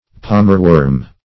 Palmerworm \Palm"er*worm`\, n. (Zool.)